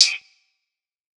Closed Hats
TS HiHat_9.wav